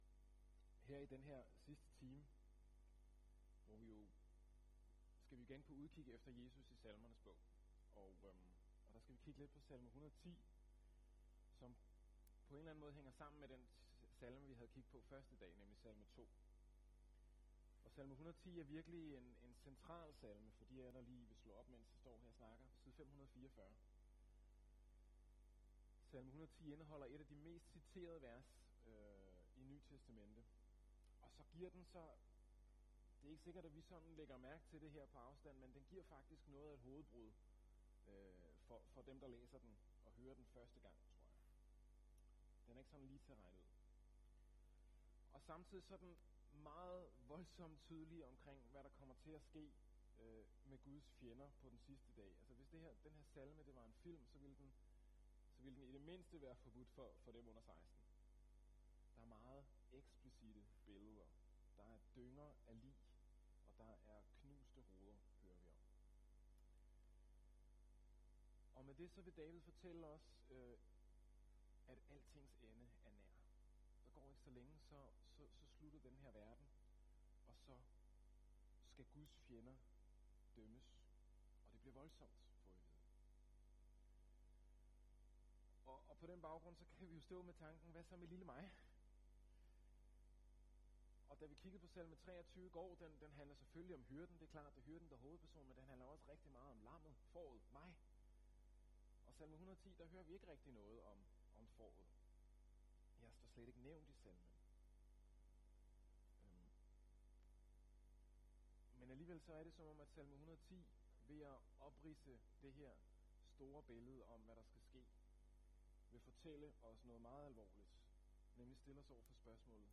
Menighedslejr på Virksund.